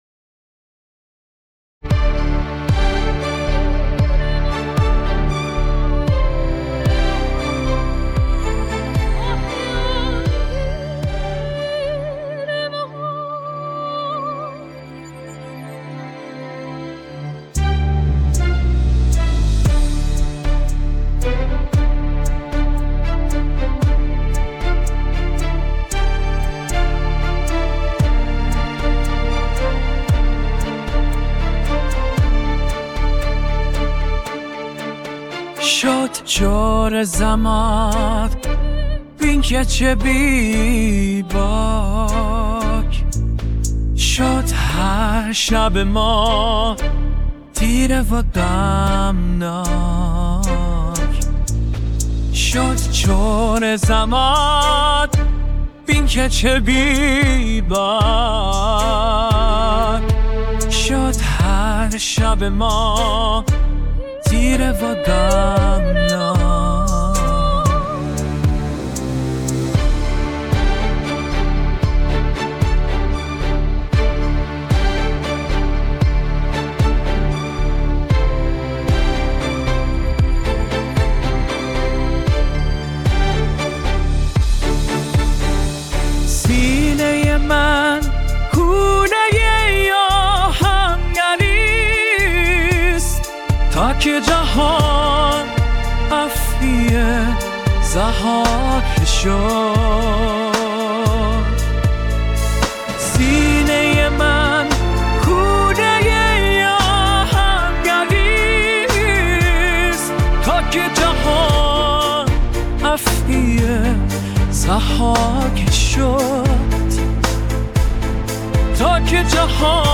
خانه» آهنگ‌های میهنی و حماسی